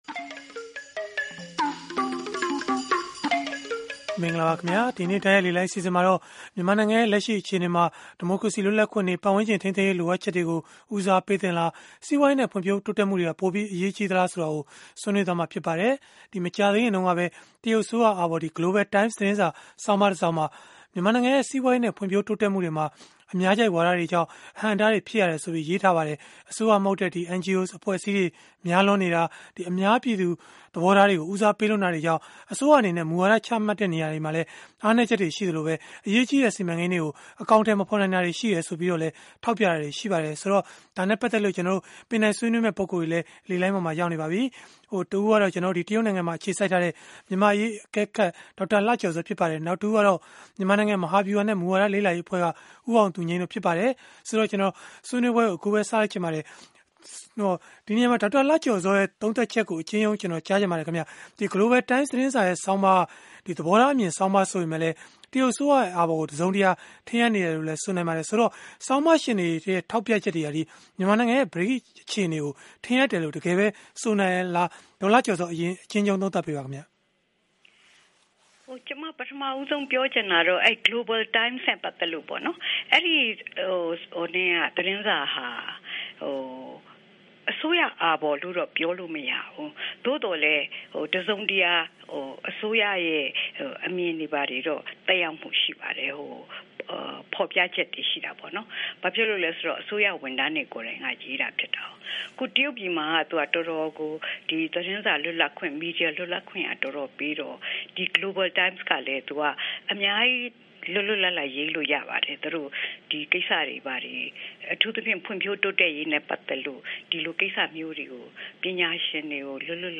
တိုက်ရိုက်လေလှိုင်း အစီအစဉ်မှာ